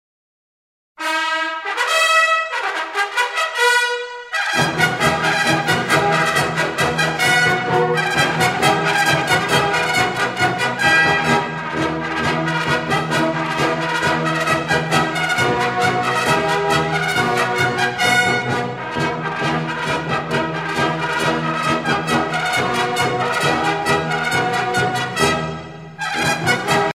militaire
Pièce musicale éditée